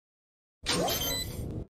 Defuse Spike